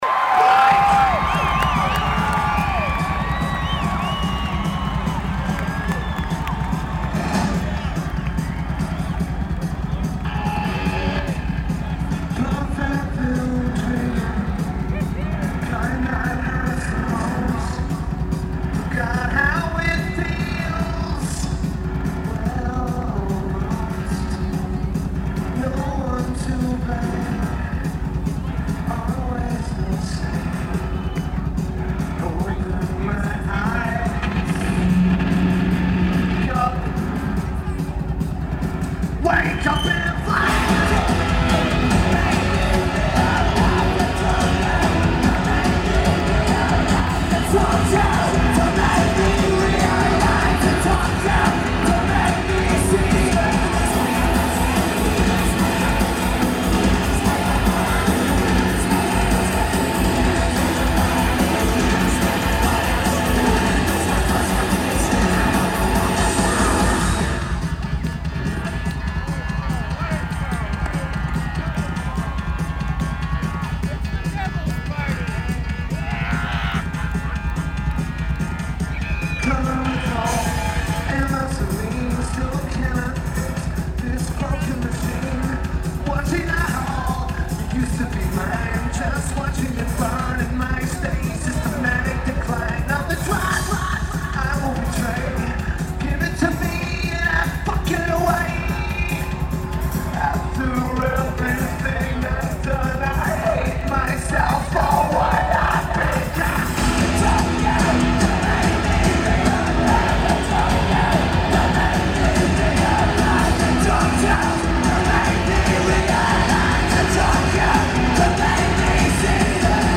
Thomas & Mack Center
Lineage: Audio - AUD (Single Stereo Sony Mic + Sony WM-D6C)